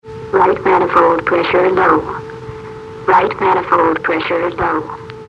B-58 Voice Warning Messages
RightManifoldPressureLow.mp3